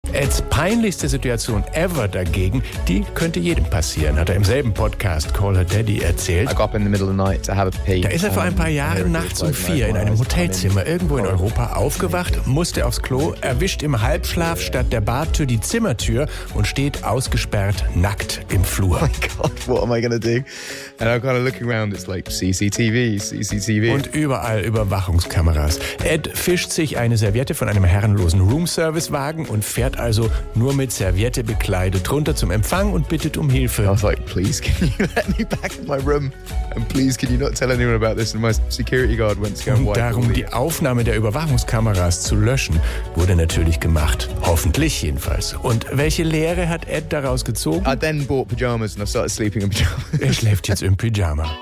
Musiknews der Woche